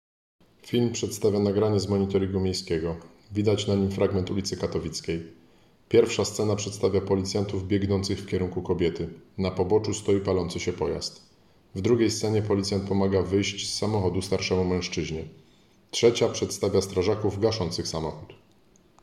Nagranie audio Audiodeskrypcja_do_filmu.m4a